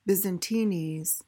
PRONUNCIATION:
(biz-uhn-TEE-nyz, BIZ-uhn-tee-nyz)